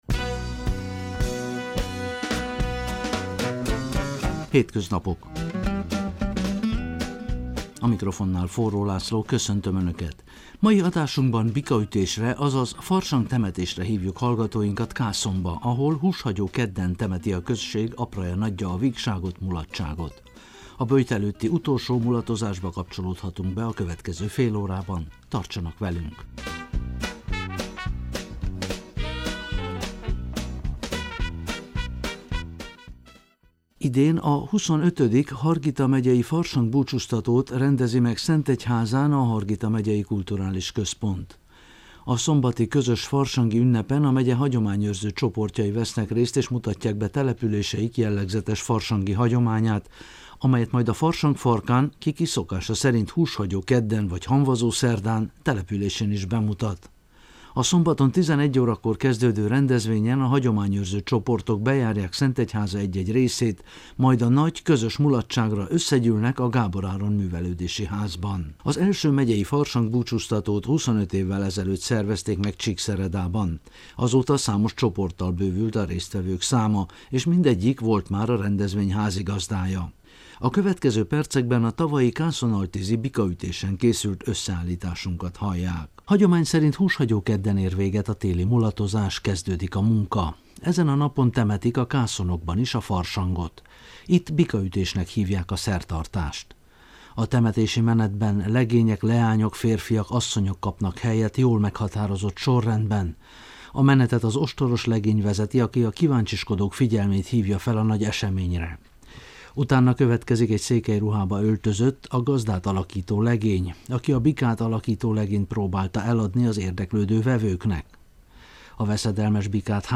Adásunkban Bikaütésre, azaz Farsangtemetésre hívjuk hallgatóinkat Kászonba, ahol húshagyó kedden temeti a község apraja nagyja a vígságot, mulatságot. A tavalyi böjt előtti utolsó mulatozásba kapcsolódhatunk be a következő fél órában, tartsanak velünk.